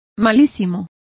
Complete with pronunciation of the translation of chronic.